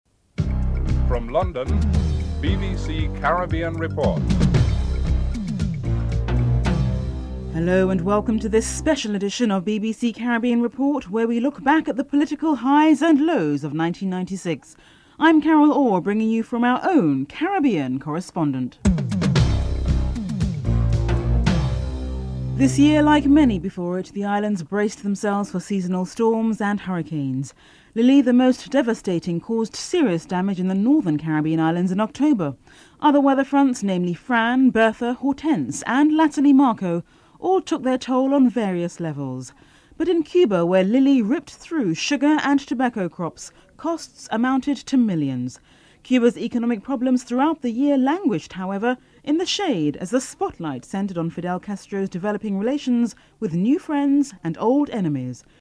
The British Broadcasting Corporation
6. Our final dispatch comes from Montserrat where the rumblings of the angry Soufriere Hills volcano sent residents scurrying either to shelters to the North or to relatives and friends abroad.